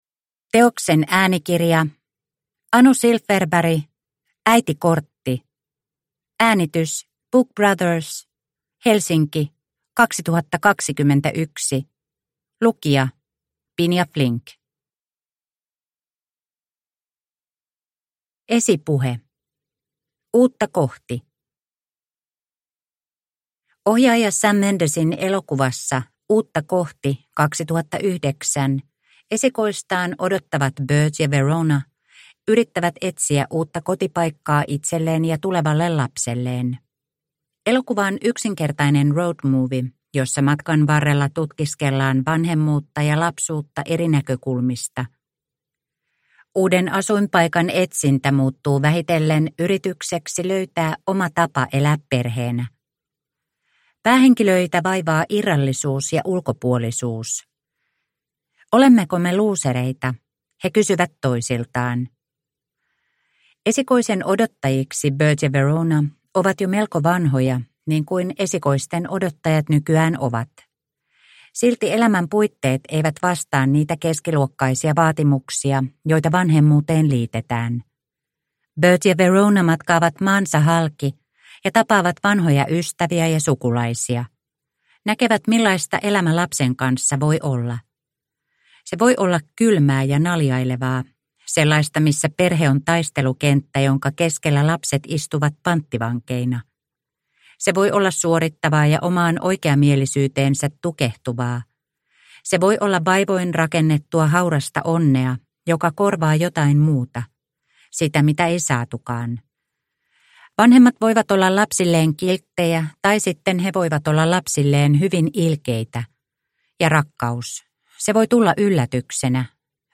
Äitikortti – Ljudbok – Laddas ner